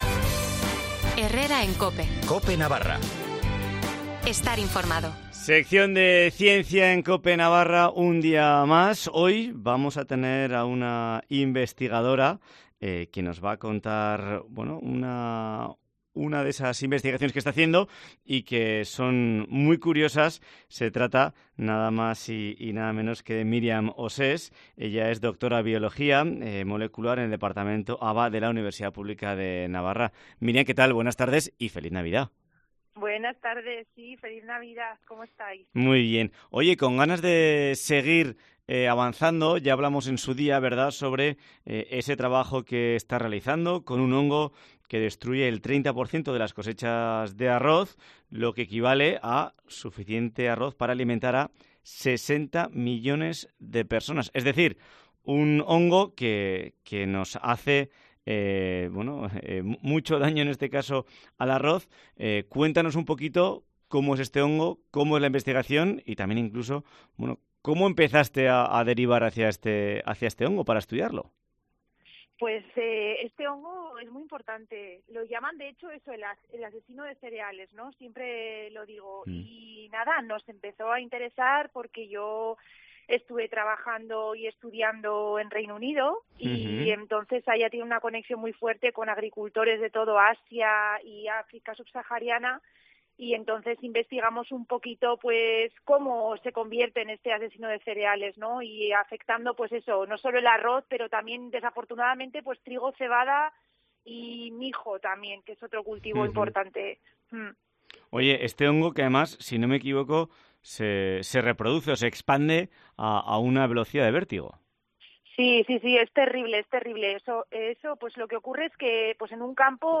Sección de ciencia en COPE: Entrevista